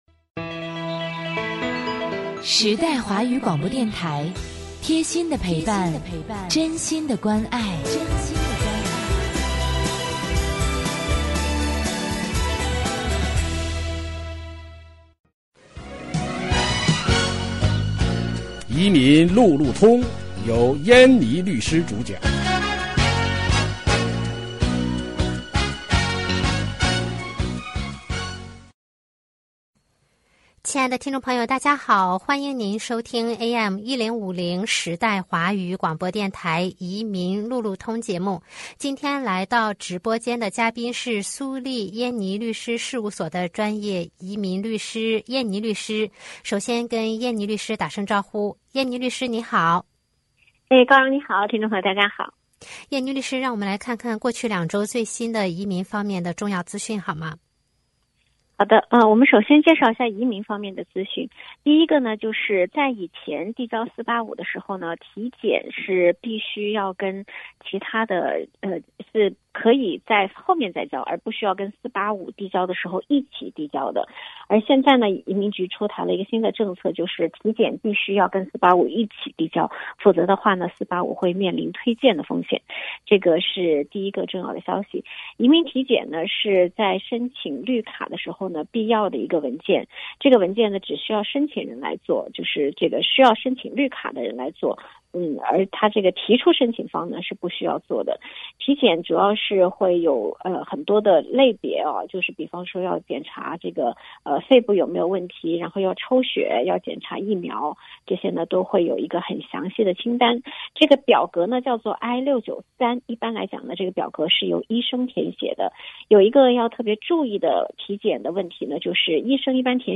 每月第二、第四个周一下午5点30分，AM1050时代华语广播电台现场直播，欢迎听众互动。